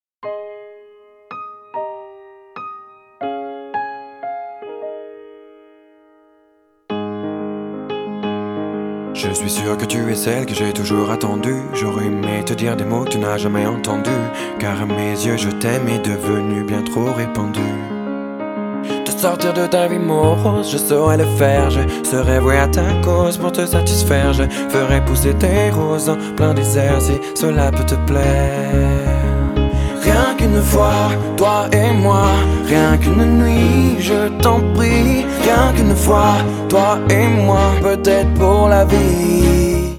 • Качество: 128, Stereo
поп
мужской вокал
спокойные
пианино